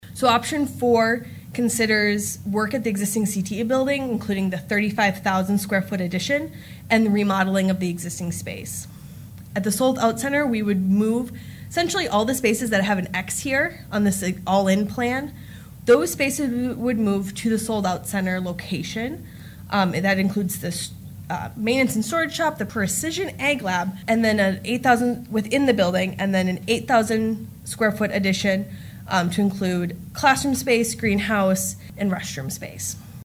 At Monday nights Huron School Board meeting JLG Architects representatives were on hand for a facilities planning update.  Following previous enrollment studies, long and short range proposals were presented for the Huron School District.